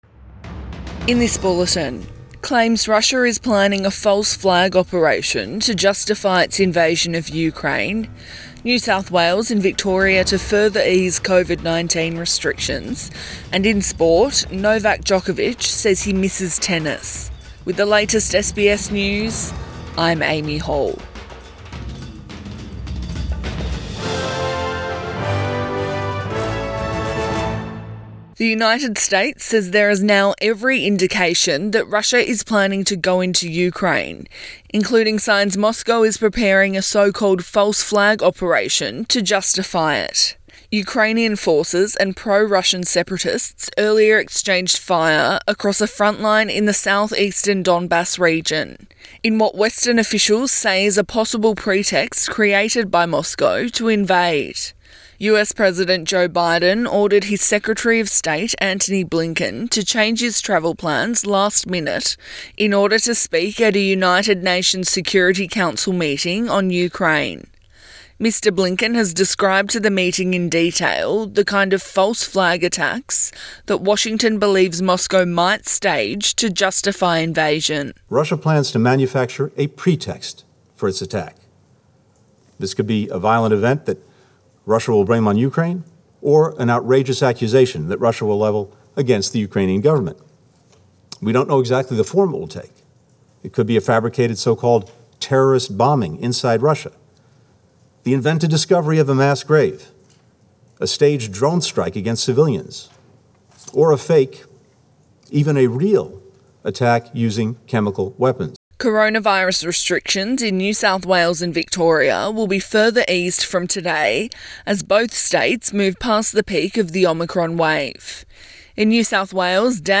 AM bulletin 18 February 2022